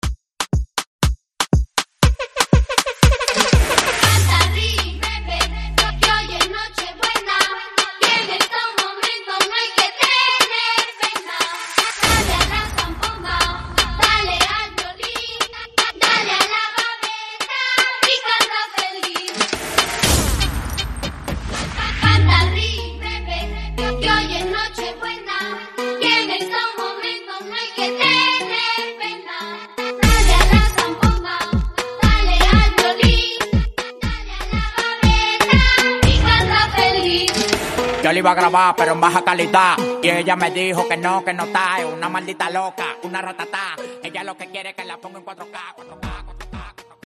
Dembow